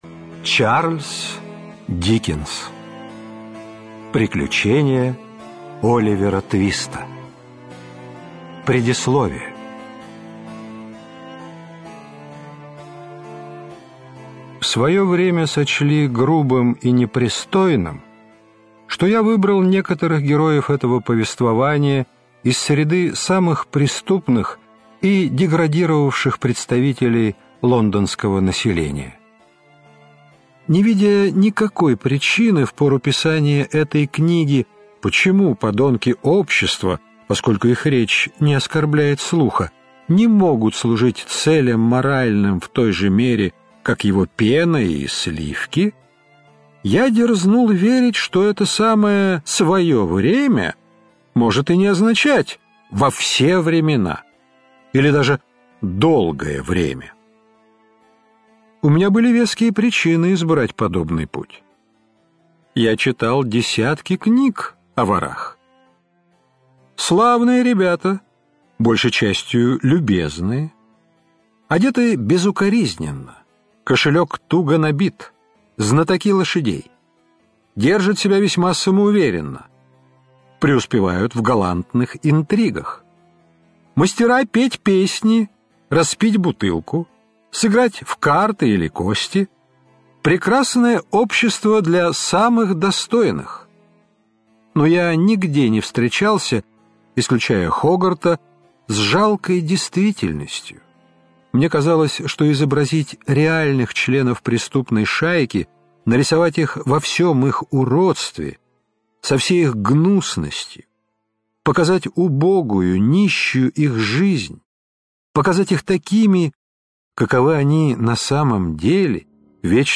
Аудиокнига Приключения Оливера Твиста - купить, скачать и слушать онлайн | КнигоПоиск